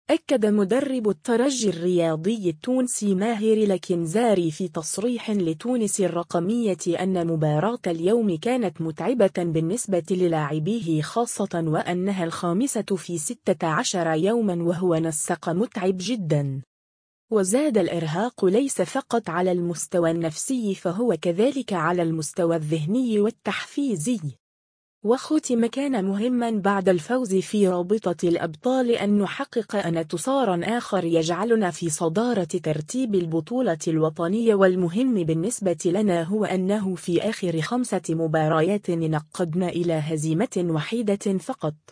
أكّد مدرّب الترجي الرياضي التونسي ماهر الكنزاري في تصريح لتونس الرقمية أنّ مباراة اليوم كانت متعبة بالنسبة للاعبيه خاصة و أنّها الخامسة في 16 يوما و هو نسق متعب جدا.